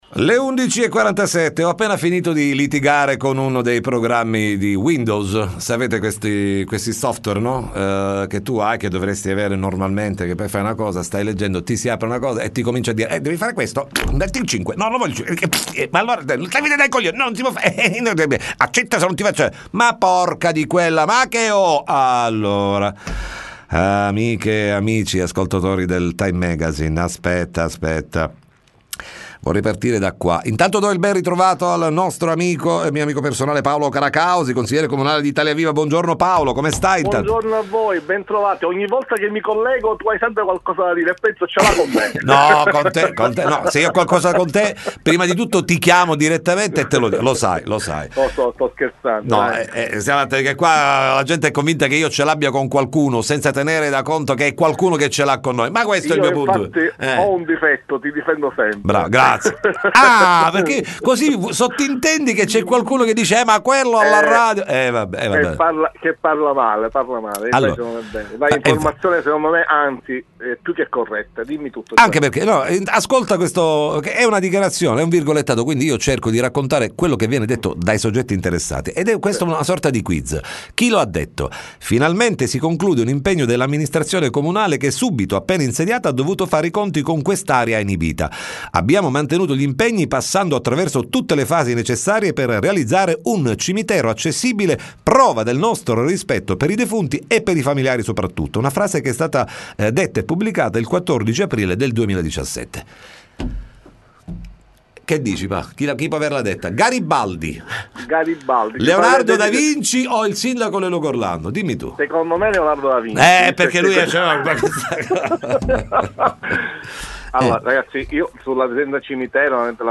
Riascolta l’intervista al consigliere comunale Paolo Caracausi (ITALIA VIVA)
TM intervista Paolo Caracausi